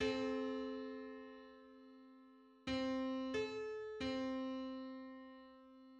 File:3375th harmonic on C.mid - Wikimedia Commons
3375th_harmonic_on_C.mid.mp3